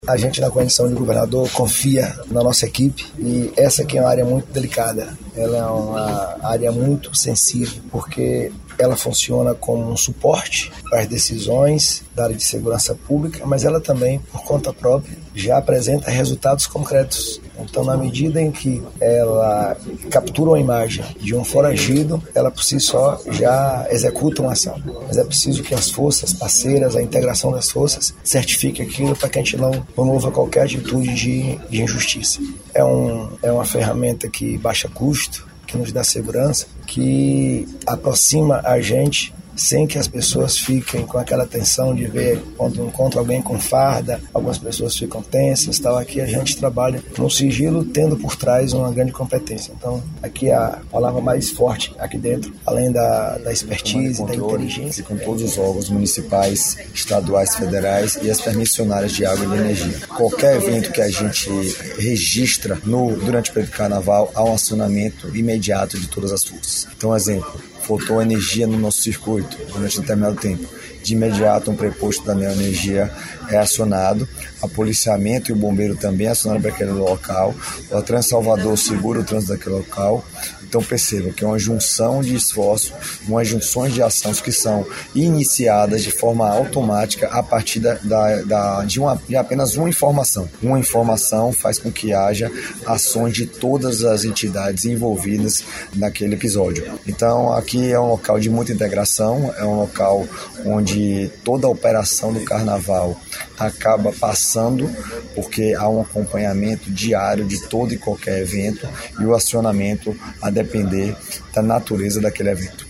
🎙O Secretário da Segurança Pública, Marcelo Werner, que acompanhou o governador na visita ao CICC e explica o funcionamento da unida